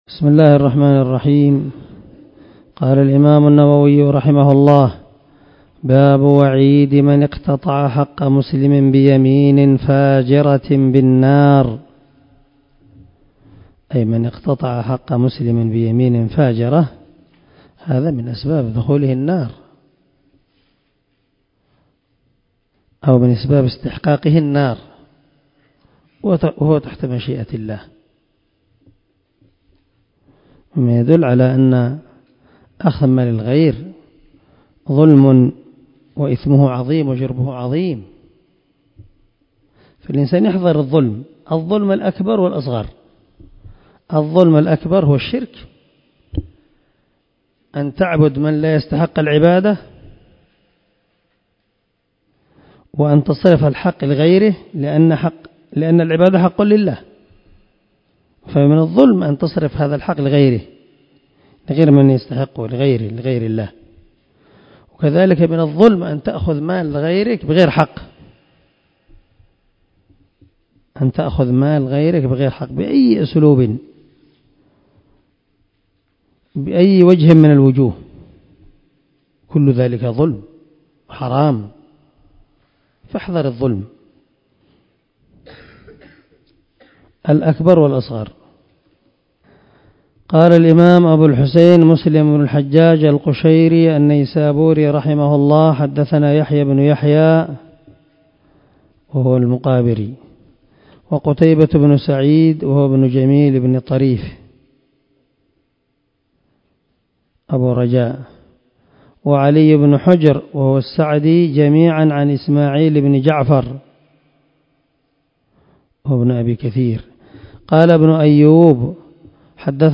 099الدرس 98 من شرح كتاب الإيمان حديث رقم ( 137 ) من صحيح مسلم
دار الحديث- المَحاوِلة- الصبيحة.